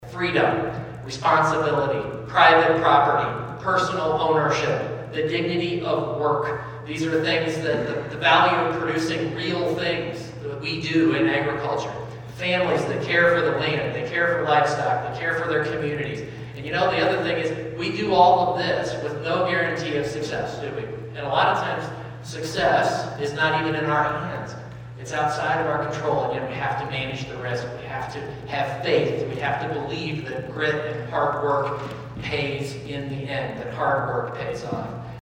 Iowa Secretary of Agriculture was in the listening area earlier this week and served as the keynote speaker for the Carroll County Farm Bureau’s annual meeting and member appreciation dinner.